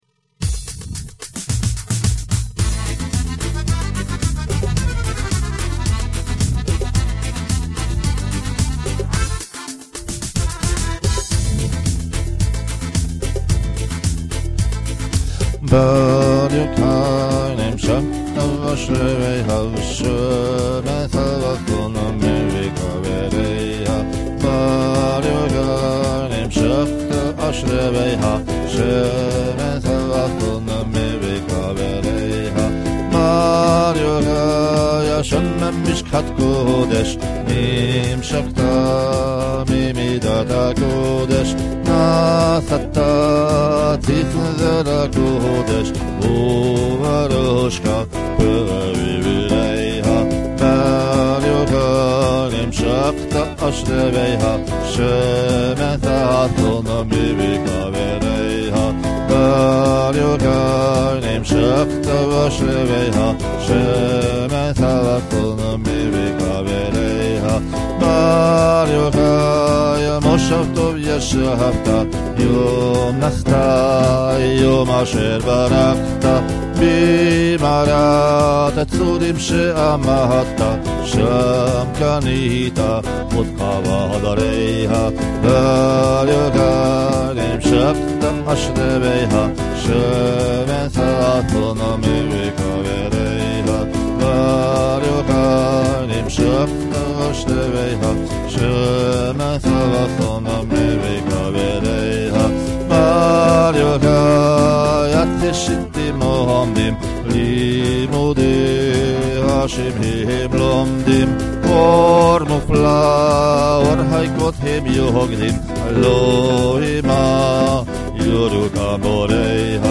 egy rendkívül ismert dal